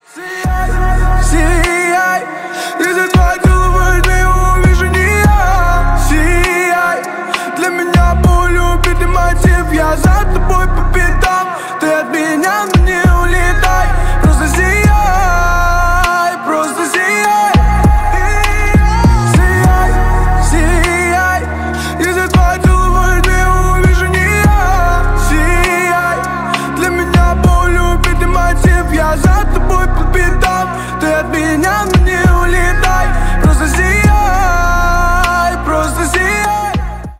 басы
рэп